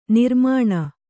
♪ nirmāṇa